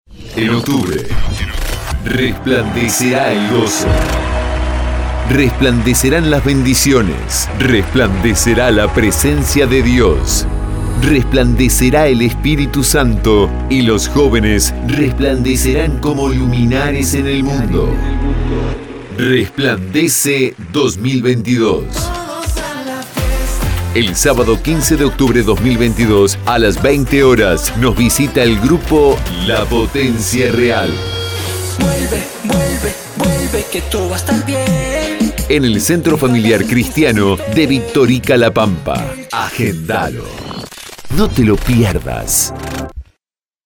• Spot para boliches, bares y eventos nocturnos. Con edición dinámica.